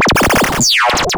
Glitch FX 32.wav